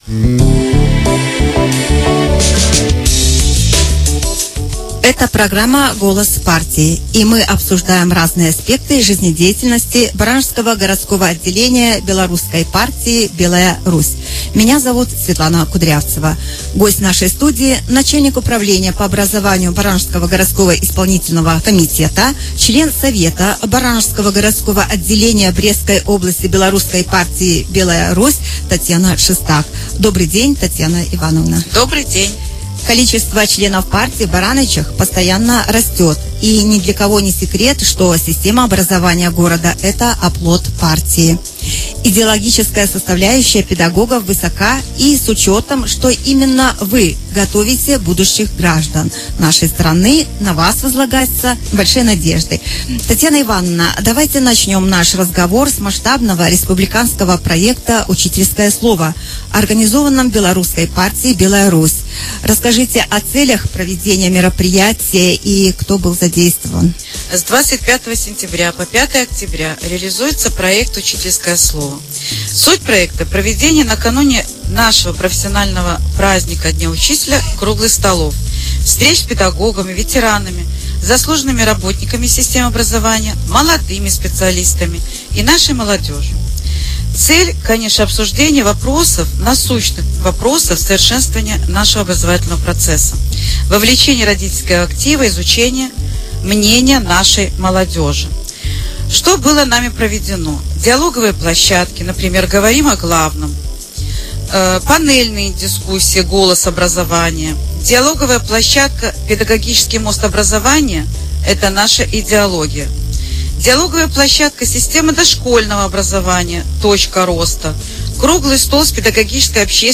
Гость студии — начальник управления по образованию Барановичского горисполкома, член Совета Барановичского городского отделения Брестской области Белорусской партии «Белая Русь» Татьяна Шестак.